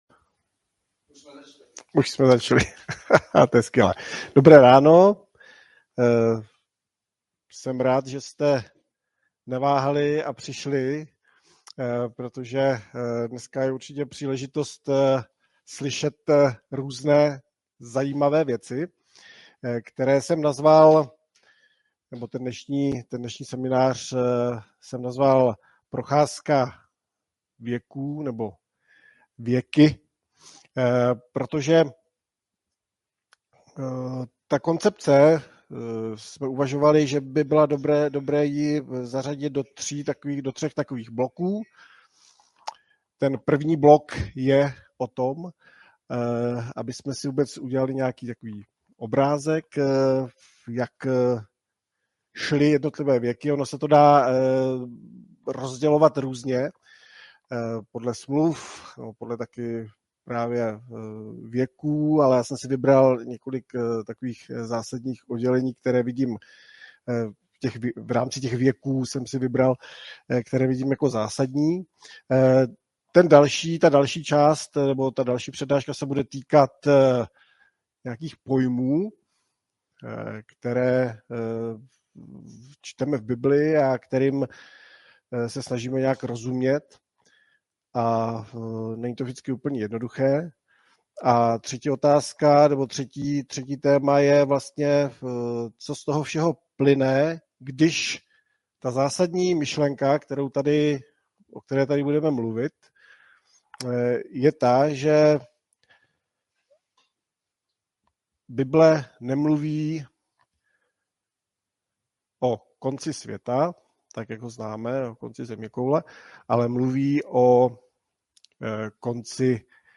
První ze série tří přenášek